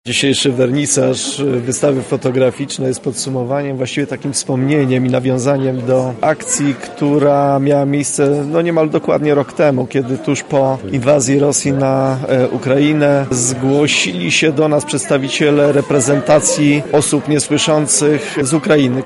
• opowiada rektor UMCS Radosław Dobrowolski